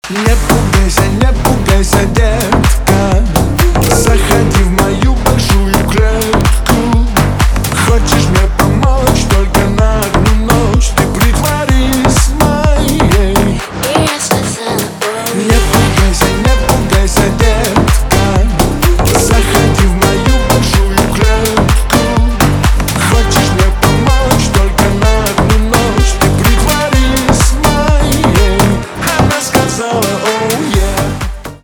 поп
ремиксы
битовые , басы , качающие